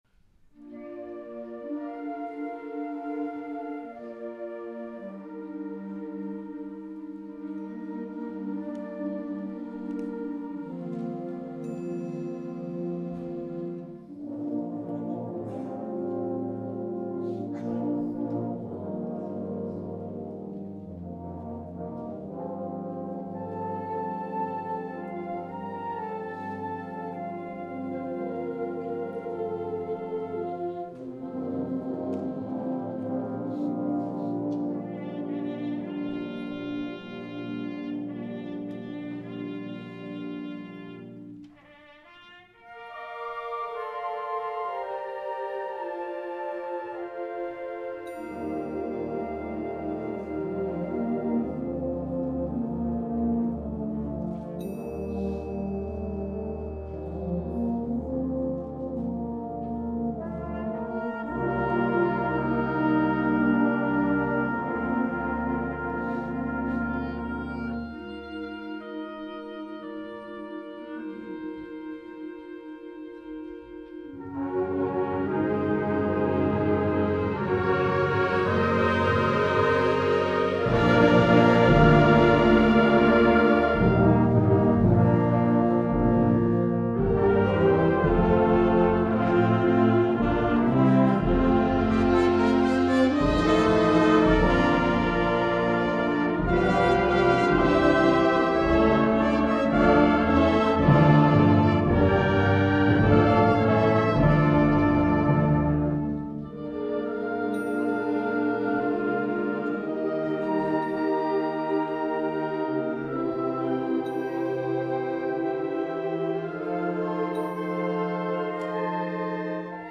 2011 Winter Concert